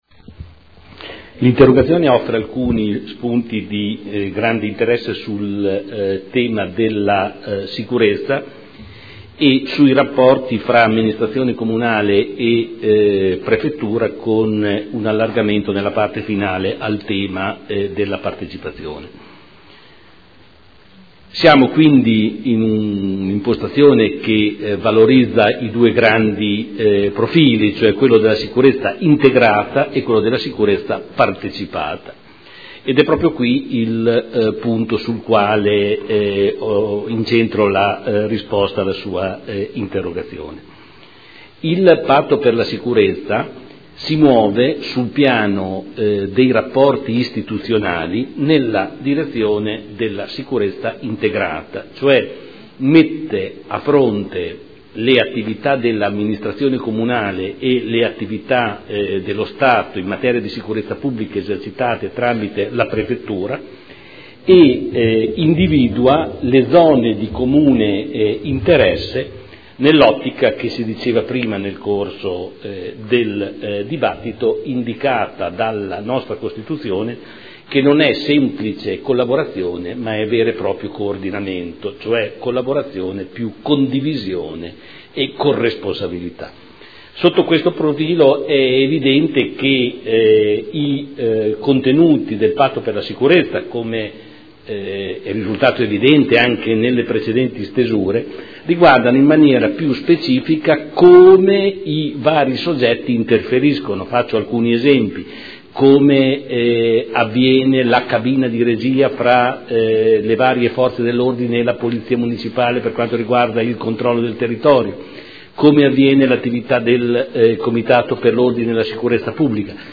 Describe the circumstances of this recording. Seduta del 03/02/2014. Audio Consiglio Comunale